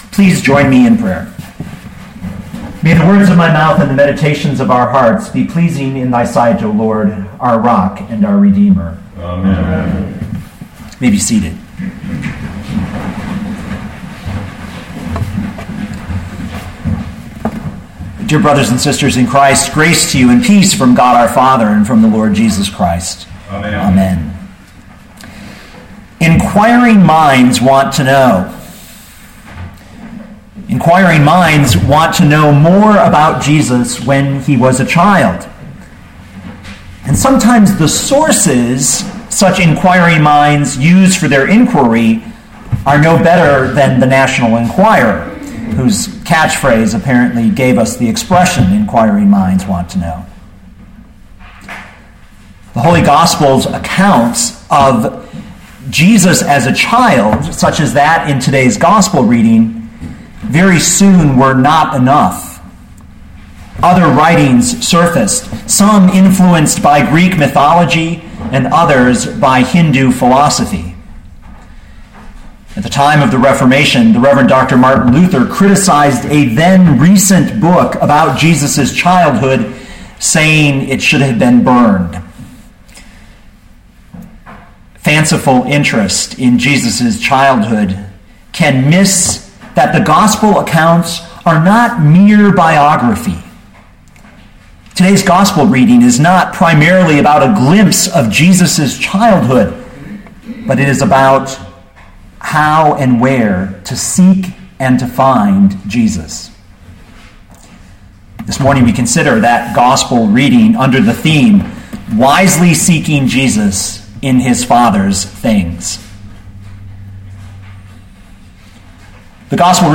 2014 Luke 2:40-52 Listen to the sermon with the player below, or, download the audio.